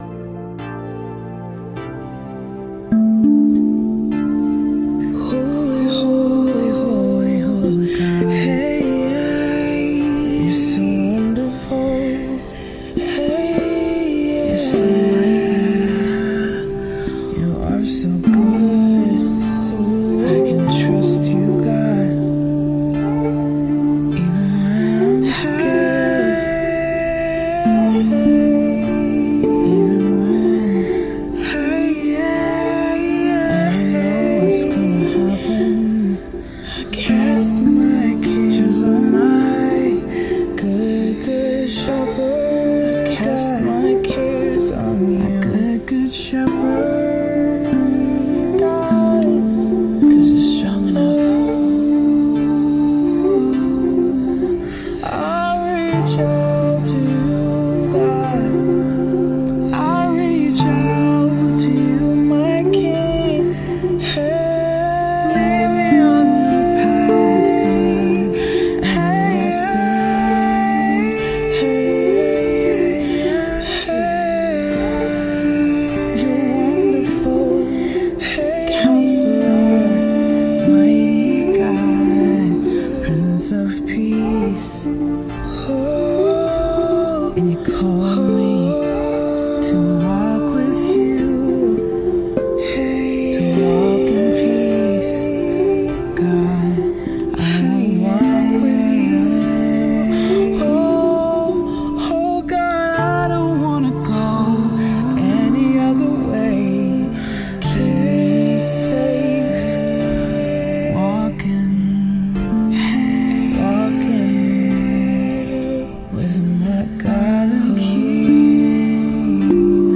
improvised worship
Rav vast drum
spontaneous worship # tongue drum